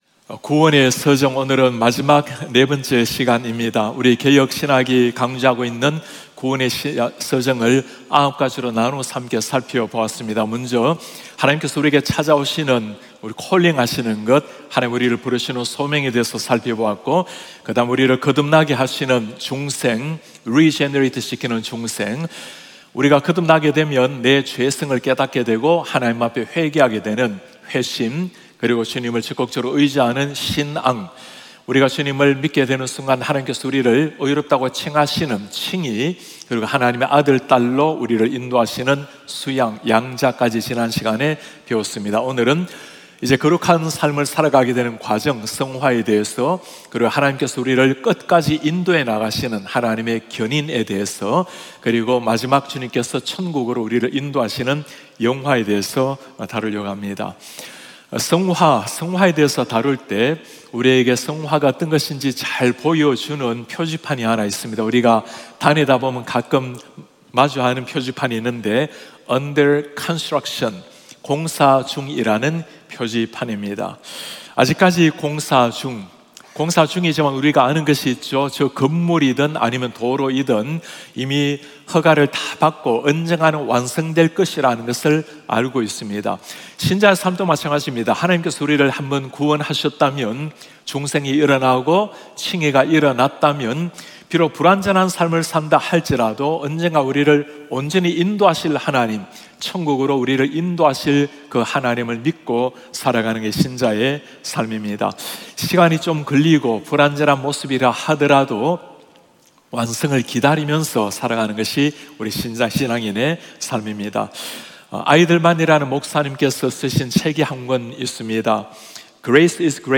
예배: 주일 예배